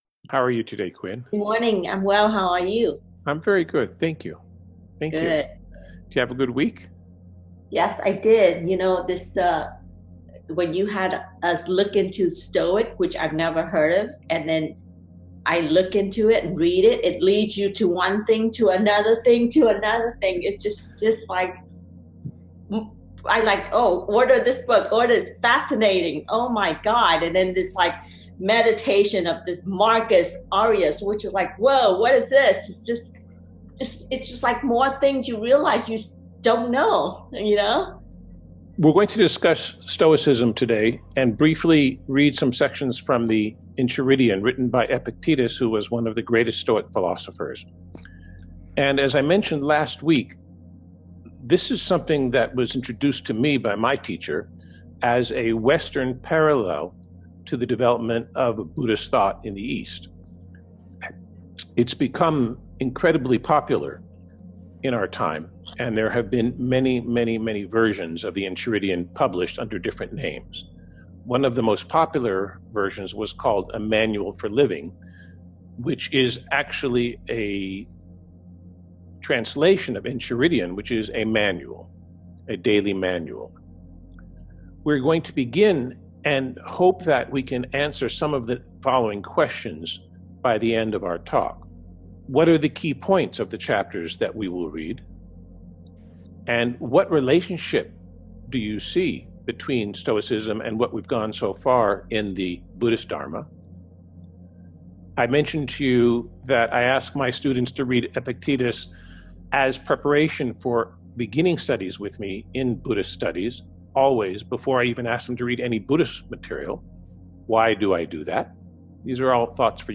In this discourse we will be discussing Stoicism, its role in society when it was written, and the present as well as how it parallels some Eastern philosophical concepts. We will read some sections of the Enchiridion and discuss.
stoicism_readings_from_the_enchiridion.mp3